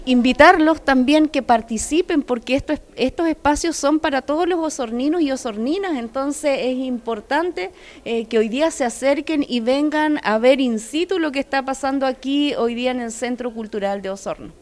Por último, la Concejala Licán invitó a la comunidad a informarse y ser parte de los distintos talleres que se entregarán por parte de la Escuela de Artesanías Guardianes de Oficios.